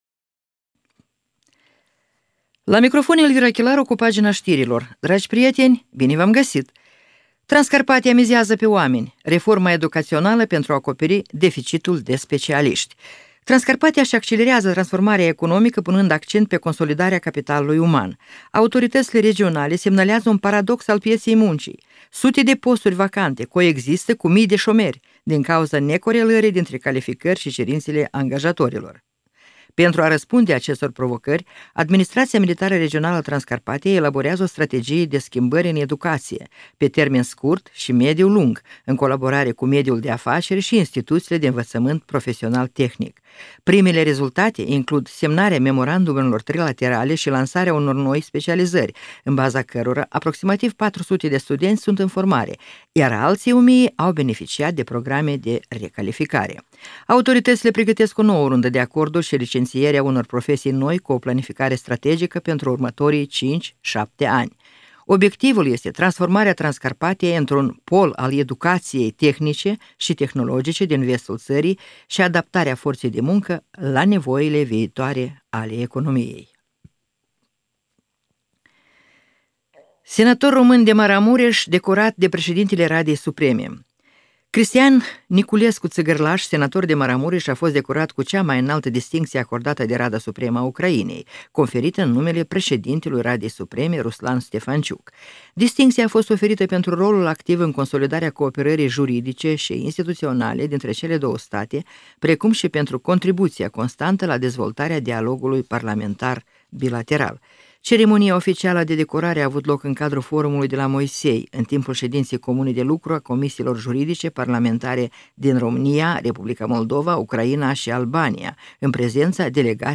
Ştiri de la Radio Ujgorod – 27.01.2026